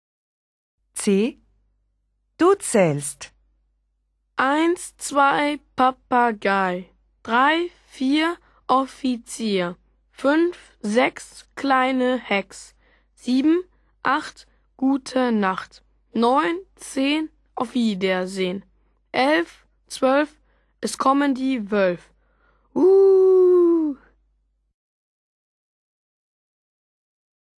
comptine.wma